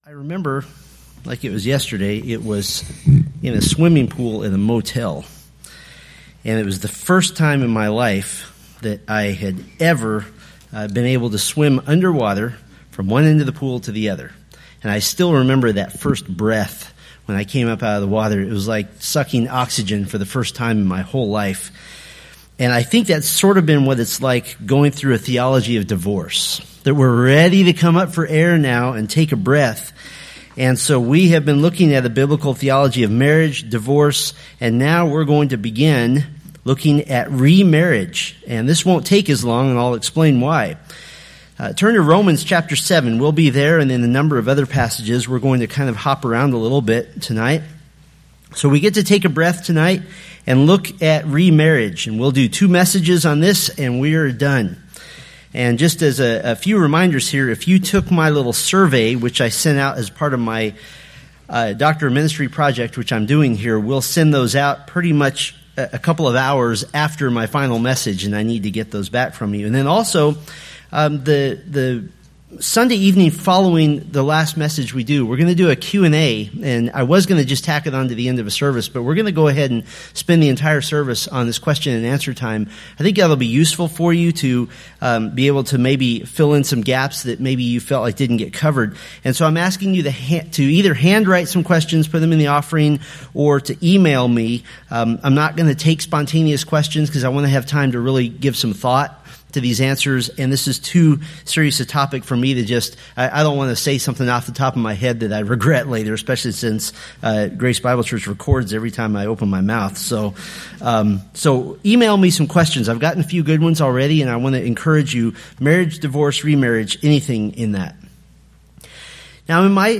Preached November 19, 2017 from Selected Scriptures